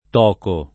toco [ t 0 ko ] s. m. (zool.); pl. tochi